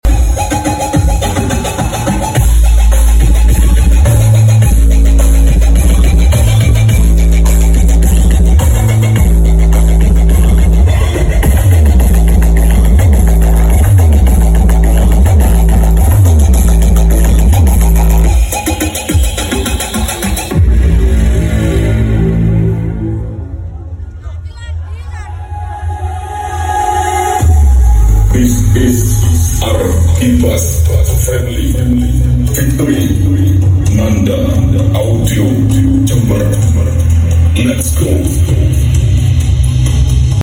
Nanda audio jember karnaval pagak